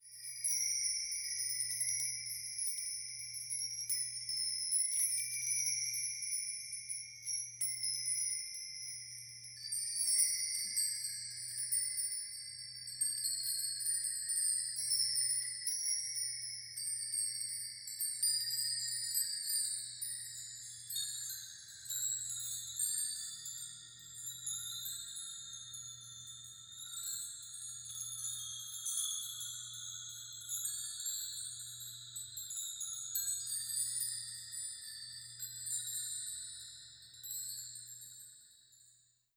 chimes